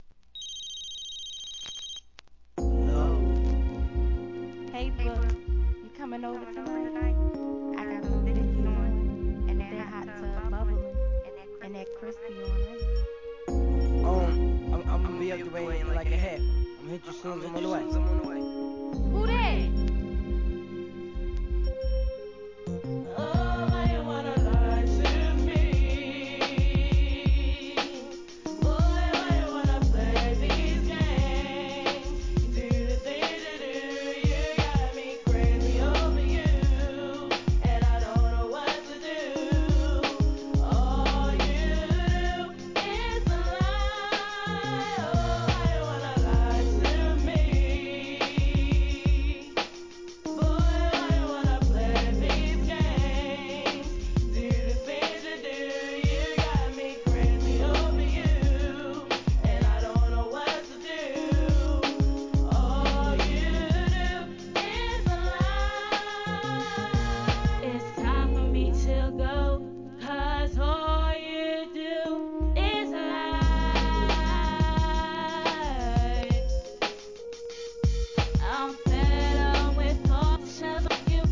G-RAP/WEST COAST/SOUTH
前半はコーラスで固めた怒メロ〜作